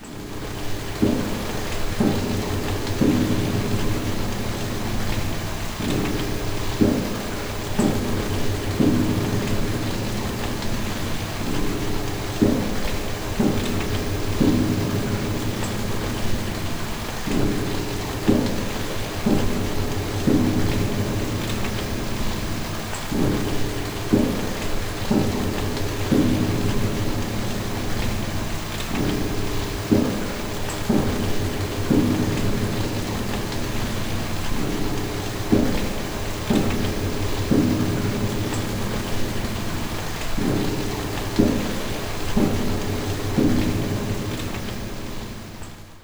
At this stage, I mixed the sound of rain recorded at submission1 and the sound of knocking on the piano lid to make environmental sounds, and made them standardised and added some effects.